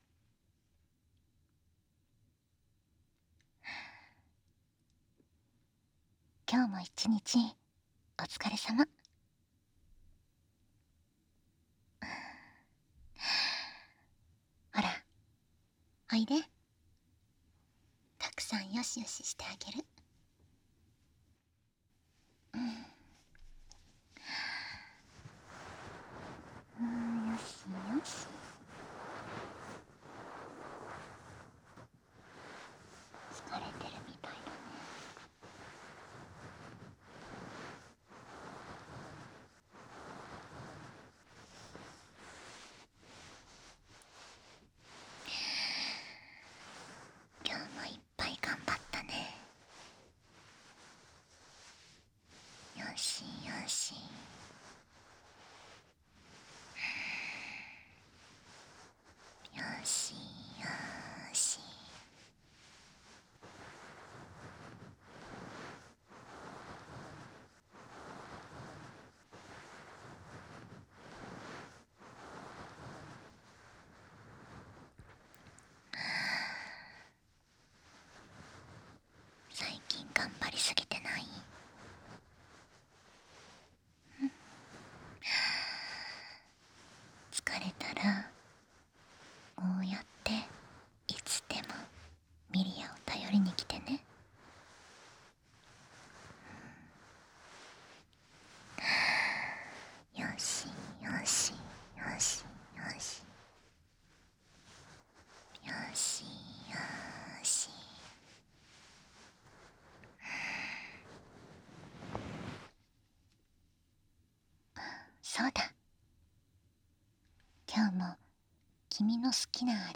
纯爱/甜蜜 萌 健全 治愈 掏耳 环绕音 ASMR VTuber
1.声のみ_今日もお疲れ様.wav